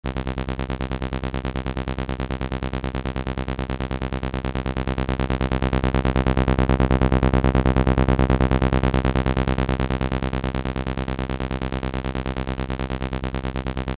Przykłady dźwiękowe uzyskane w wyniku syntezy subtraktywnej oraz ich reprezentacje widmowe:
Dźwięk wyjściowy przy stałej częstotliwości odcięcia filtru i zmieniającej się dobroci może zostać przedstawiony za pomocą następującego przykładu dźwiękowego:
Dźwięk uzyskany przy stałej częstotliwości odcięcia filtru i zmieniającej się dobroci